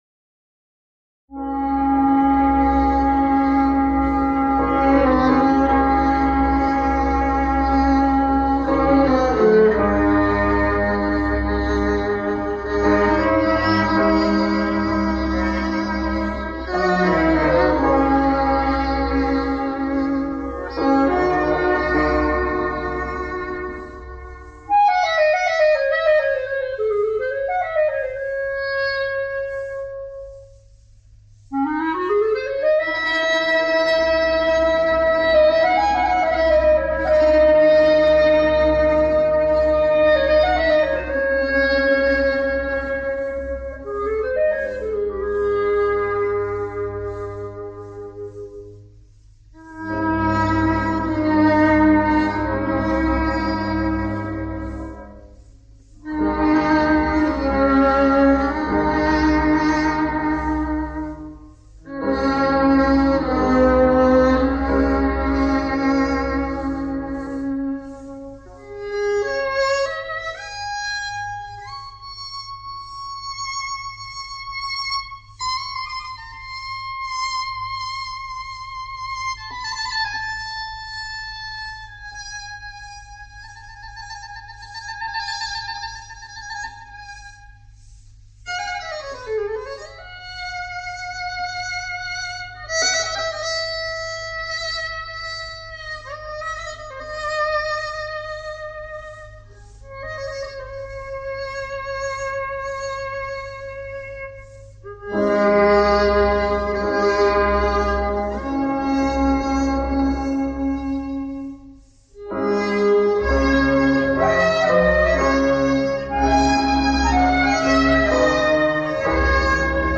اهنگ قدیمی
اهنگ سنتی